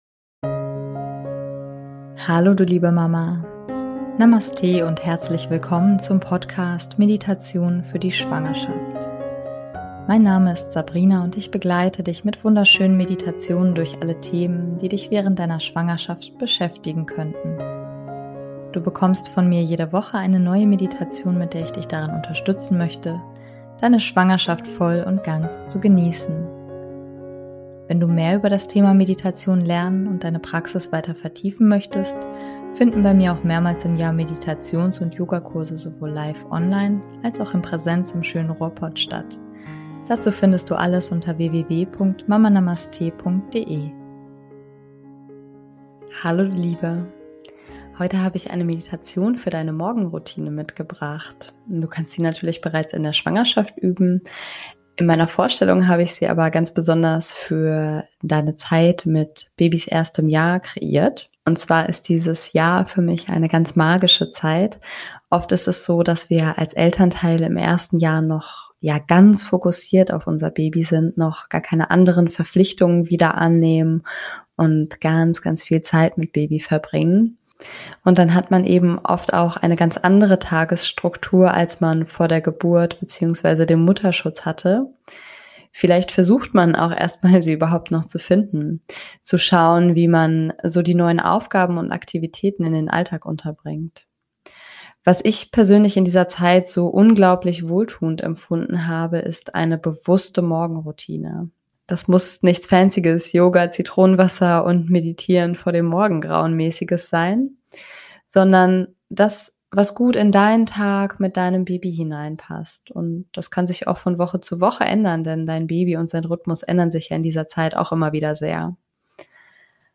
Heute haben wir eine Meditation für deine Morgenroutine mitgebracht.